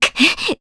Scarlet-Vox_Damage_Jp_01.wav